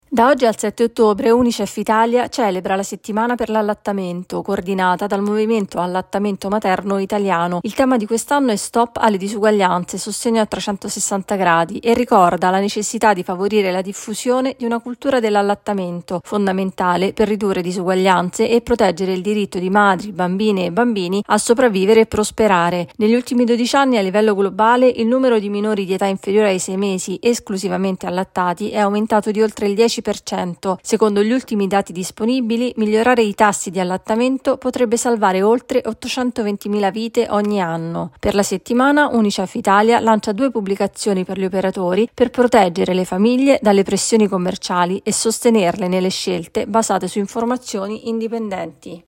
In Italia suona la campanella per milioni di studenti, ma la scuola pubblica continua a restare indietro su edilizia scolastica. Il servizio